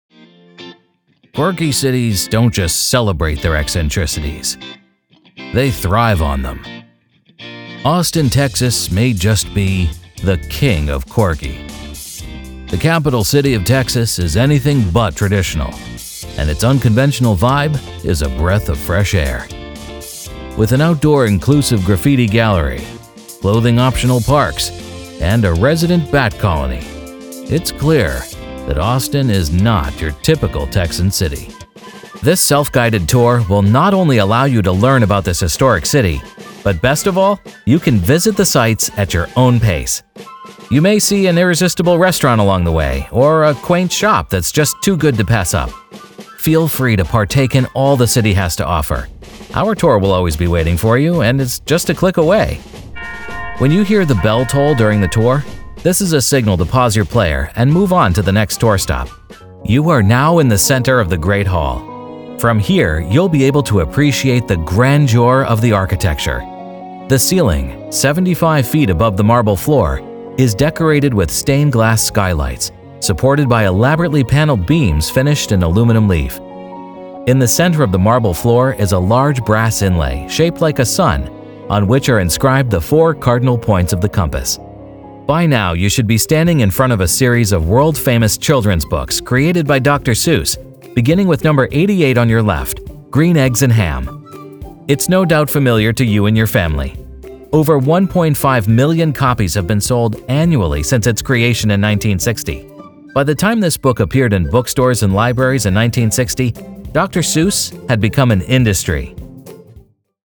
Male
English (North American)
My voice has the just-right combination of calming authority and genuine warmth.
Tour Guide
Audio Tour Demo 2
0525Audio_Tour_Demo-Demo_2.mp3